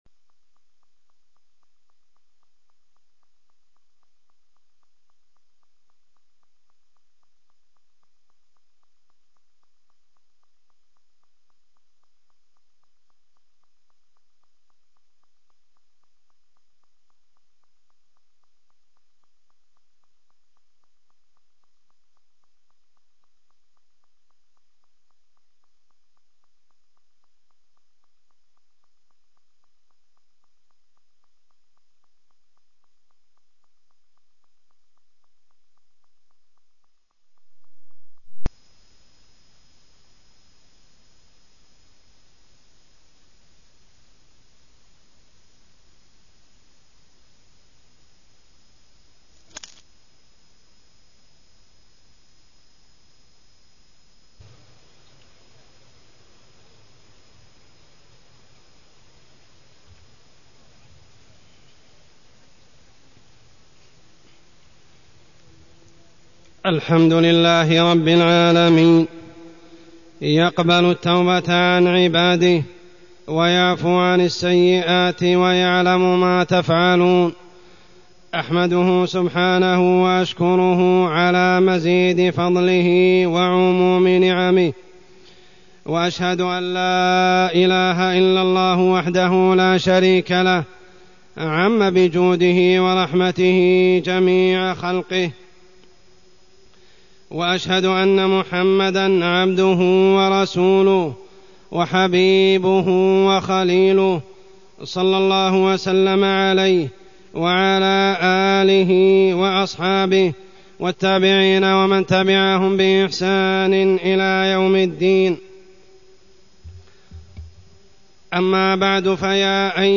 تاريخ النشر ٤ رجب ١٤٢٢ هـ المكان: المسجد الحرام الشيخ: عمر السبيل عمر السبيل الحث على الإستغفار والتوبة